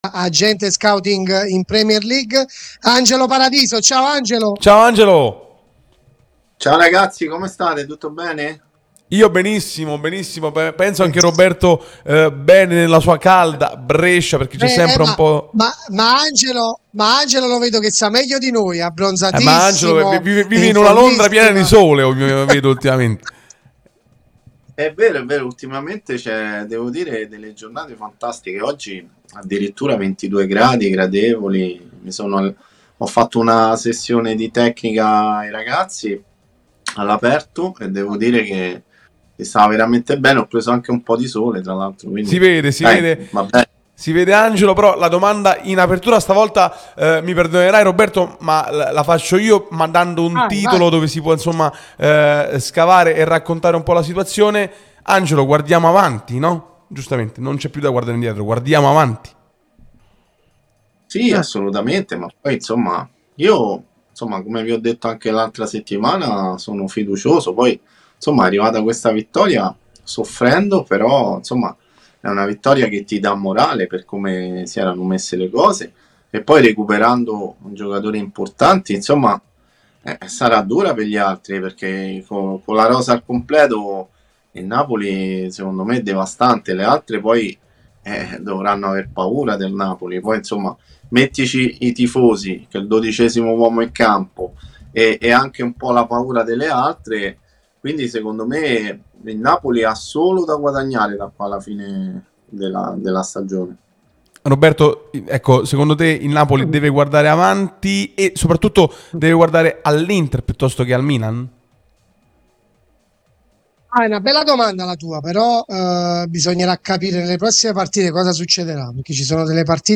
l'unica radio tutta azzurra e sempre live